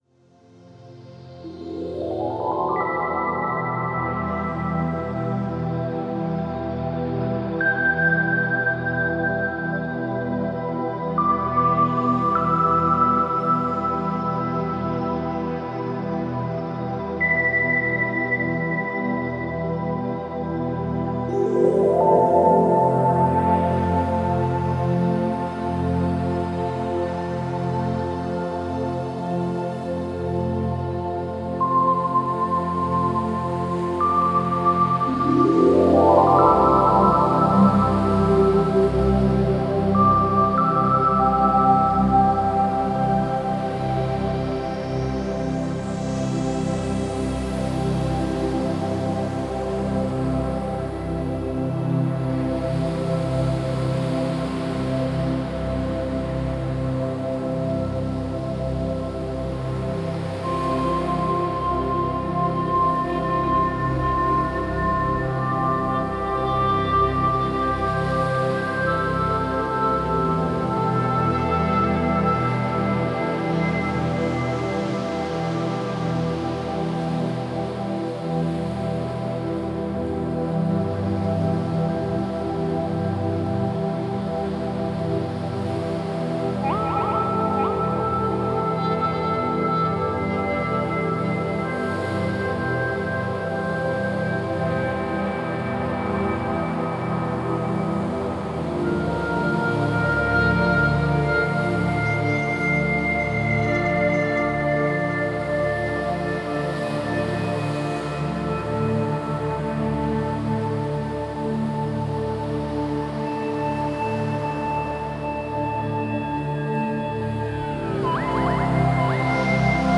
Musik zum Entspannen oder Träumen
Genre: Meditation